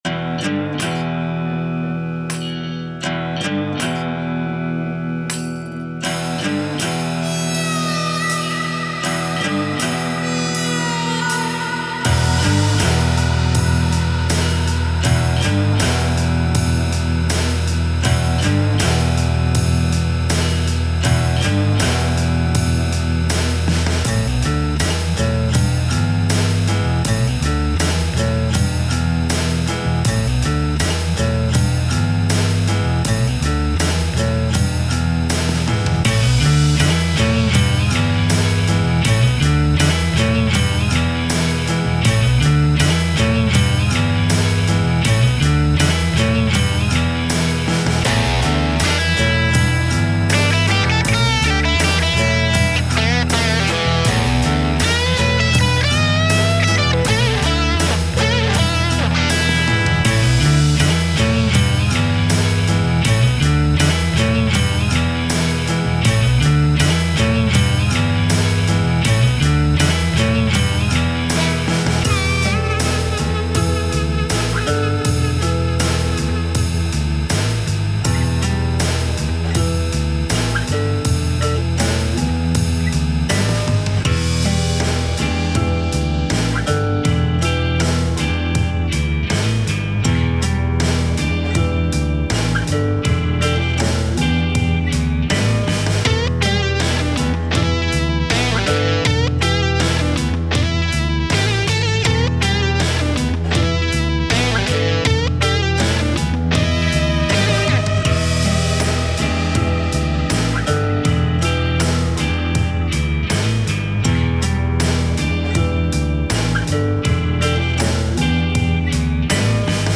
ブルージーな構成やフレーズが30代独身貴族的な雰囲気を出している(？)